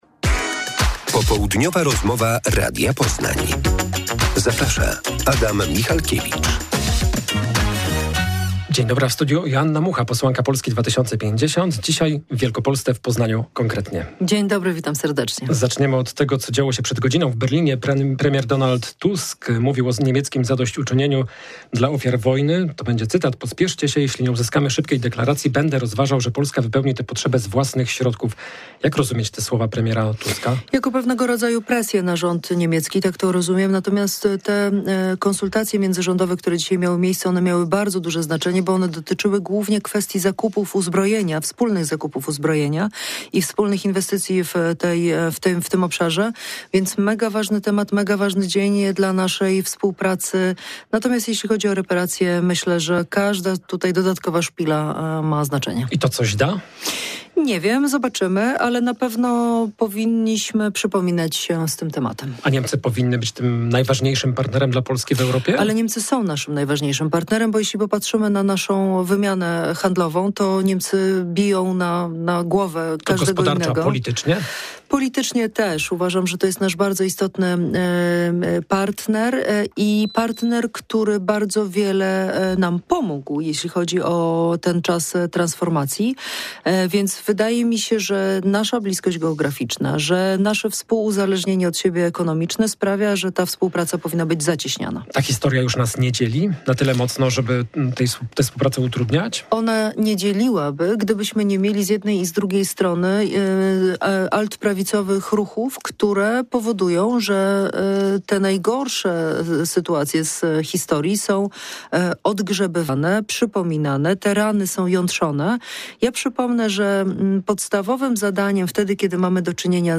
Popołudniowa rozmowa Radia Poznań – Joanna Mucha